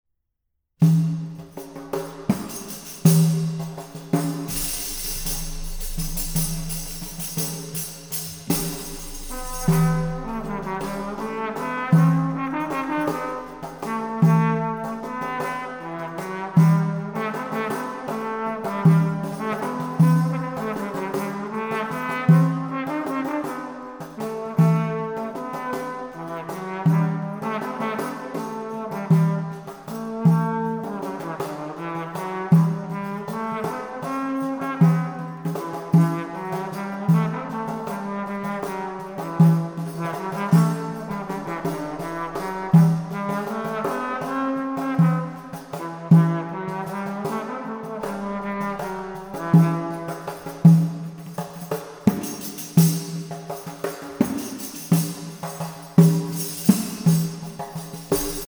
Historical Drums
Recording: Gut Hohen Luckow, 2024